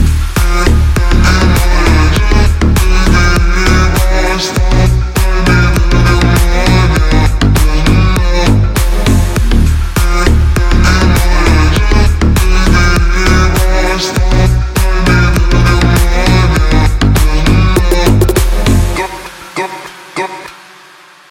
• Качество: 128, Stereo
танцевальная